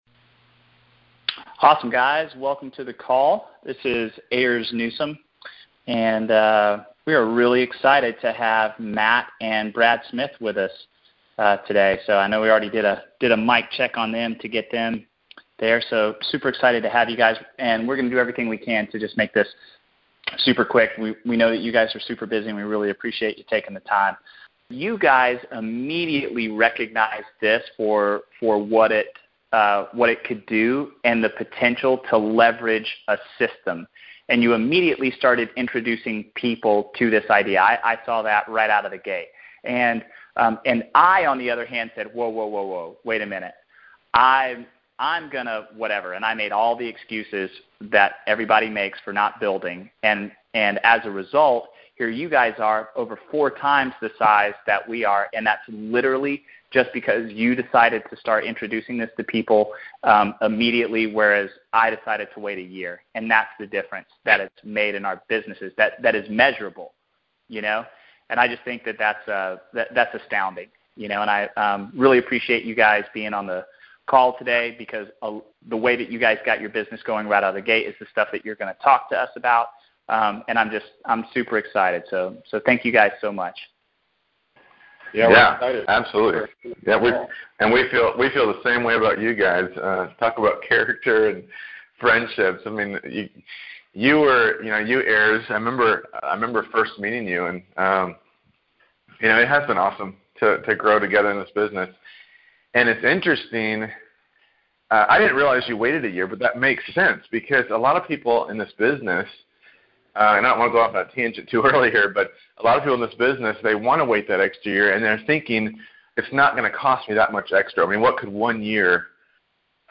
Listen to the recorded call below and then dive into the topics beneath for more details on how you can duplicate what they did.